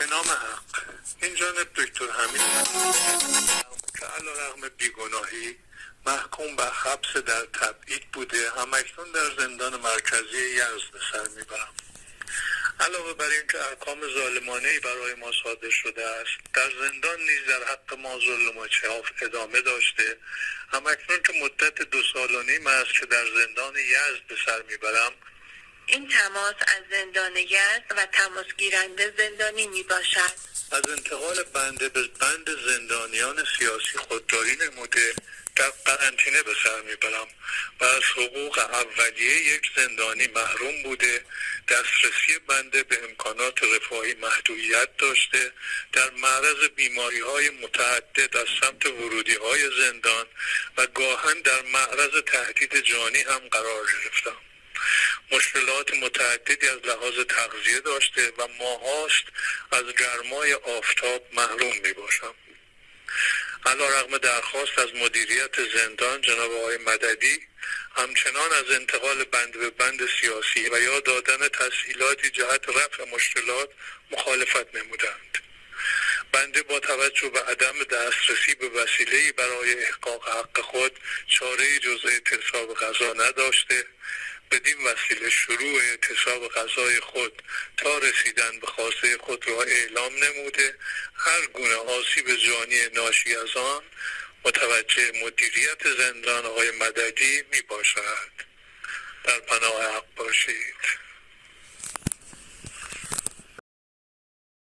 پیام صوتی